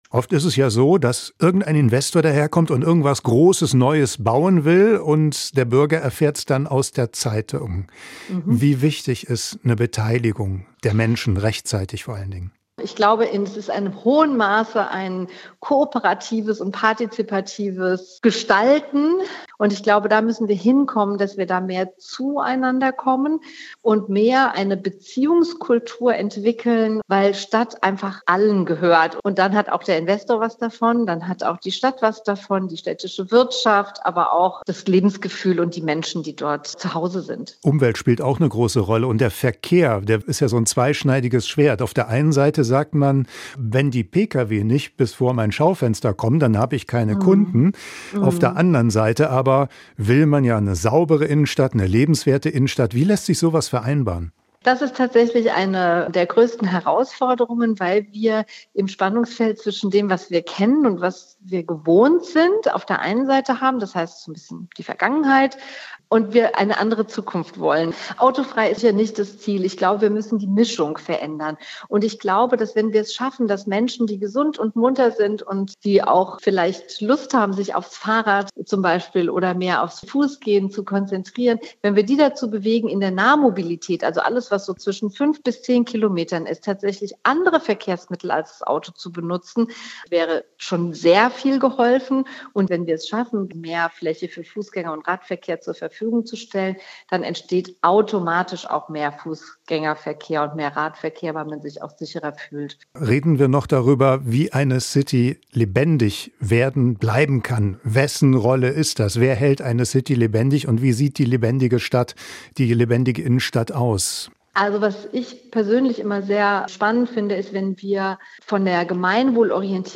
Stadtplanerin: So lassen sich Innenstädte attraktiver gestalten
Interview mit